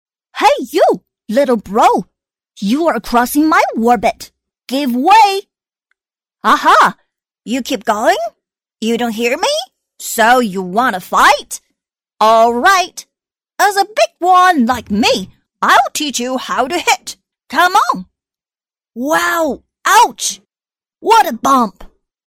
女158-英语配音角色【喜羊羊风】
女158-中英双语 可爱
女158-英语配音角色【喜羊羊风】.mp3